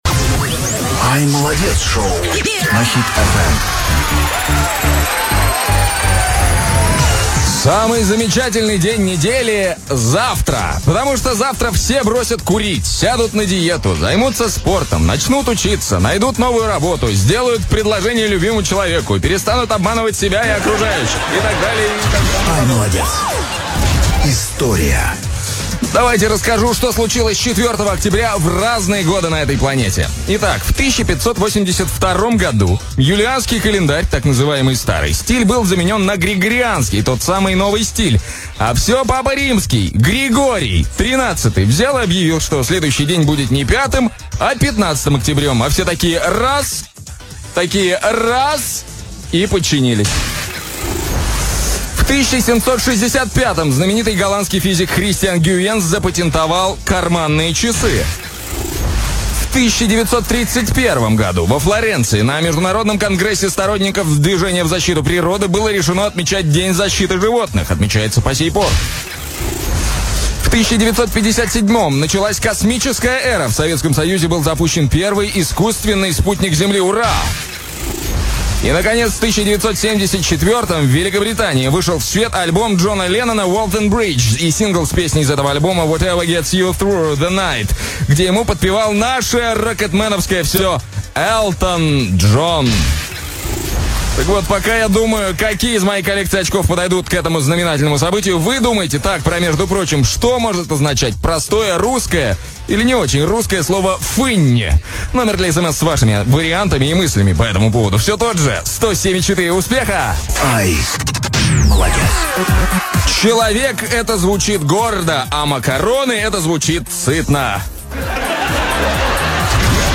"iМолодец Шоу" на Хит FM. Запись эфира.
запись эфира, утреннее шоу